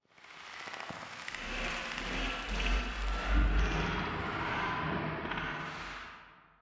Minecraft Version Minecraft Version 1.21.5 Latest Release | Latest Snapshot 1.21.5 / assets / minecraft / sounds / block / respawn_anchor / ambient3.ogg Compare With Compare With Latest Release | Latest Snapshot